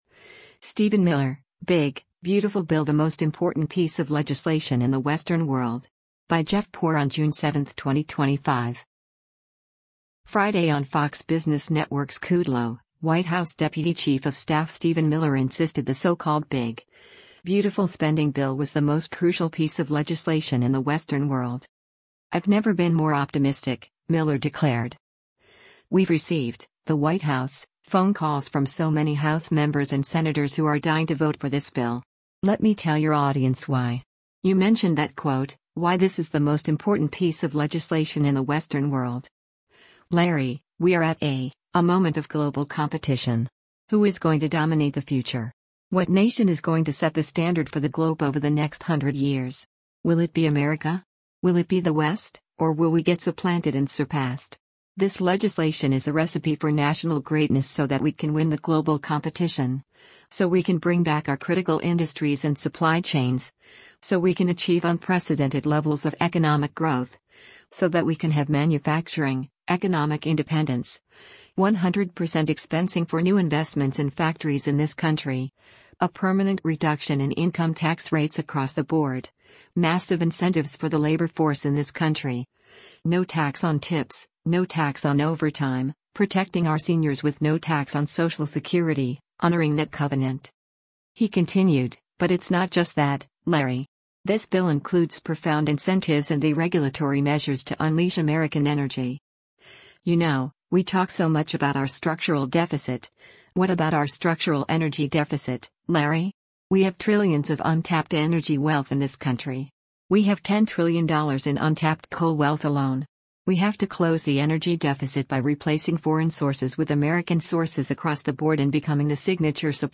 Friday on Fox Business Network’s “Kudlow,” White House deputy chief of staff Stephen Miller insisted the so-called “big, beautiful” spending bill was the most crucial piece of legislation in the Western world.